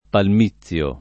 palmizio [ palm &ZZL o ] s. m.; pl. ‑zi